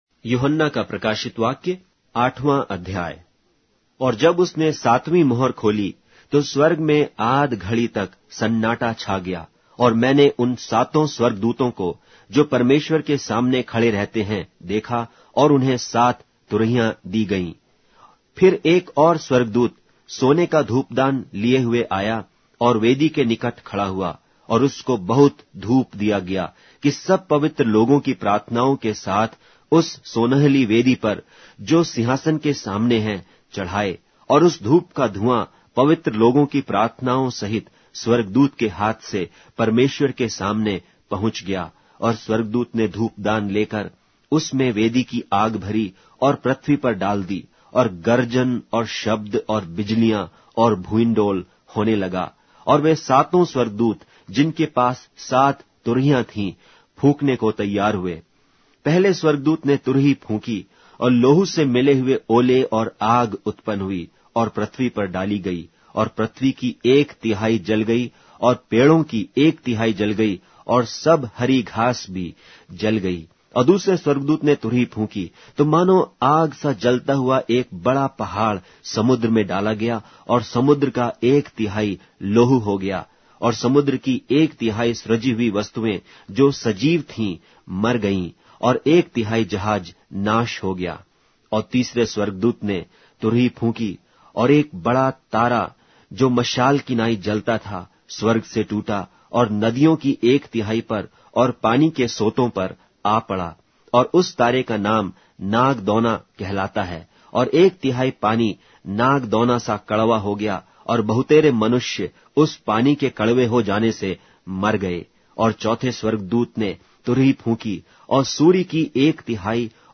Hindi Audio Bible - Revelation 15 in Net bible version